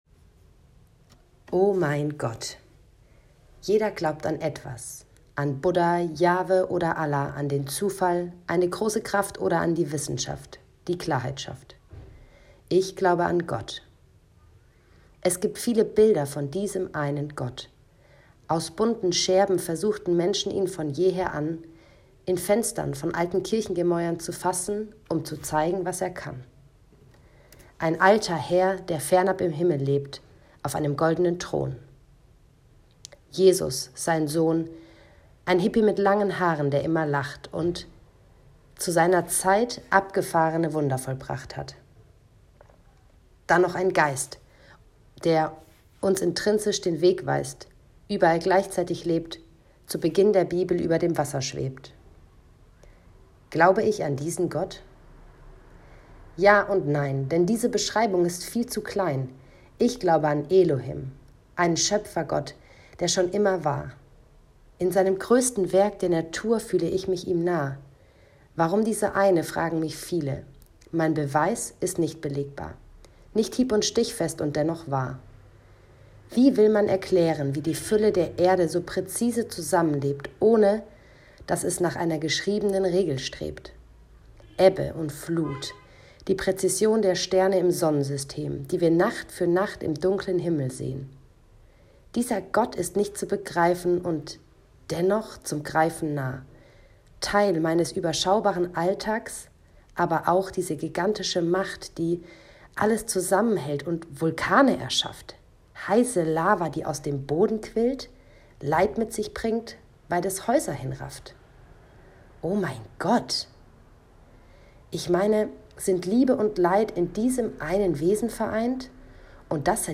Viel Freude beim Reinhören (mit autentischem Hupen im Mittelteil, willkommen in Spanien).